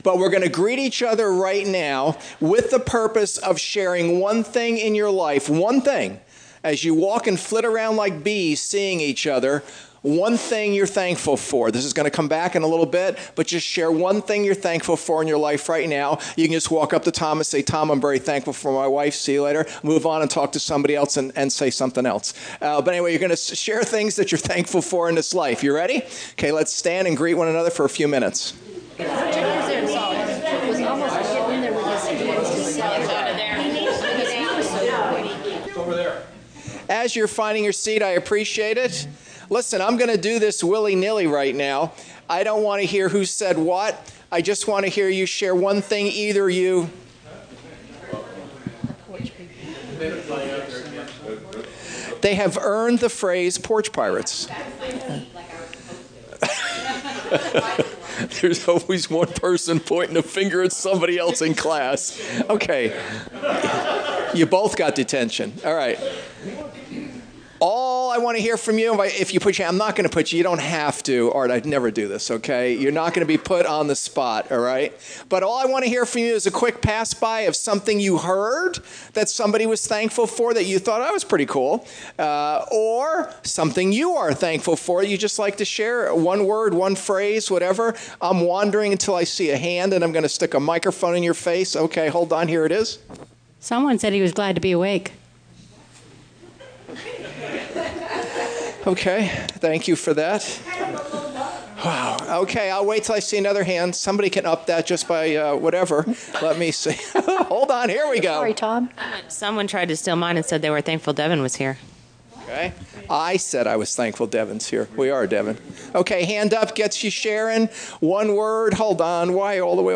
Service Type: Gathering